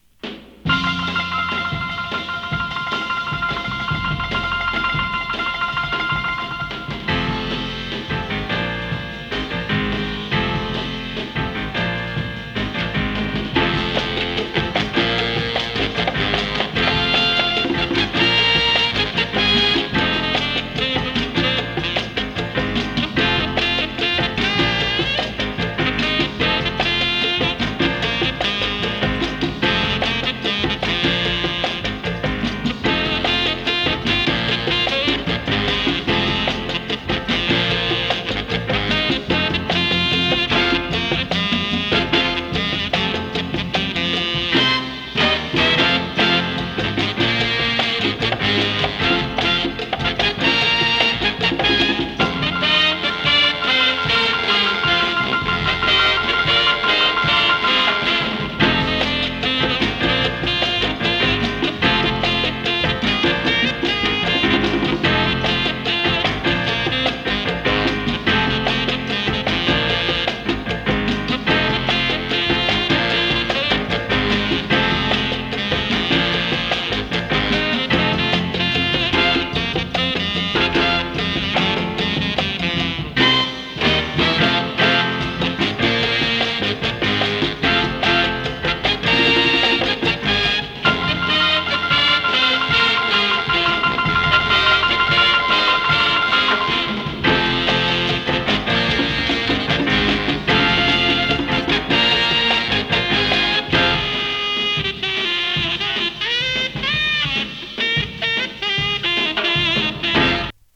Voc入り 猥雑 ブーガルー